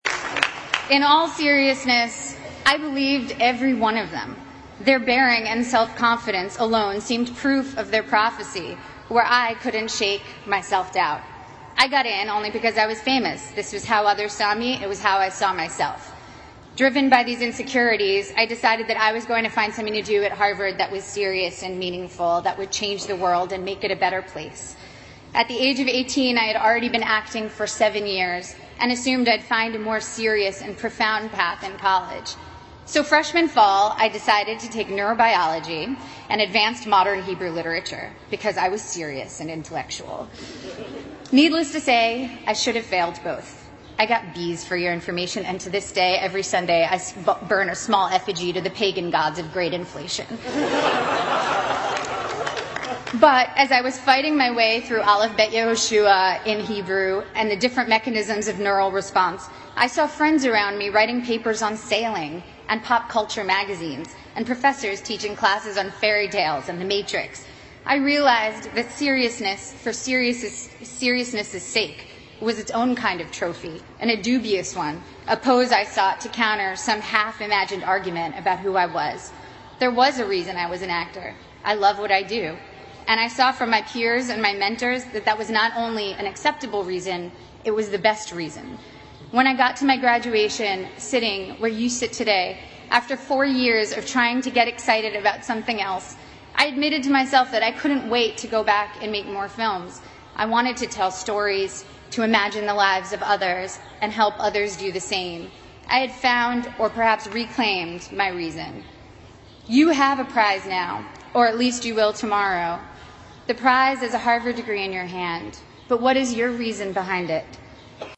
在线英语听力室娜塔莉波特曼哈佛大学演讲 第4期的听力文件下载,哈佛牛津名人名校演讲包含中英字幕音频MP3文件，里面的英语演讲，发音地道，慷慨激昂，名人的效应就是激励他人努力取得成功。
娜塔莉波特曼2015哈佛毕业典礼演讲，王力宏在牛津大学的演讲以及2013年奥普拉在哈佛大学的毕业演讲等名人在哈佛大